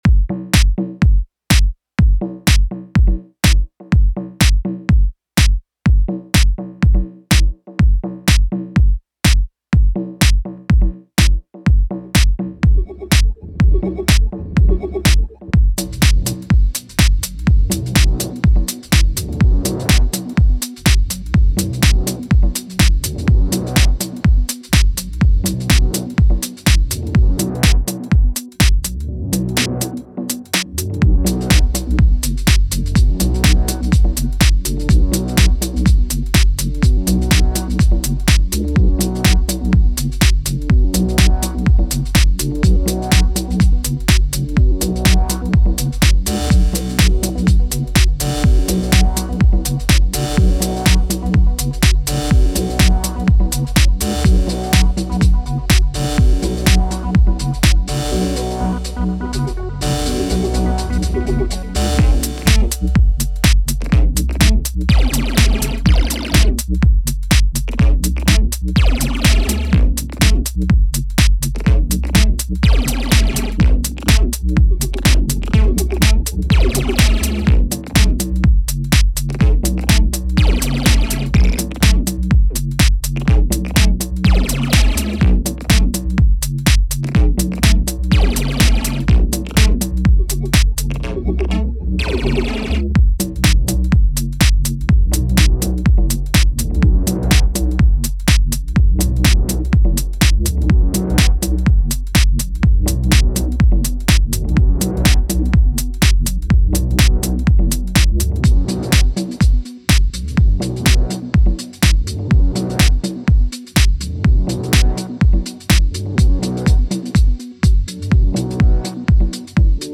We recorded the session in secrecy.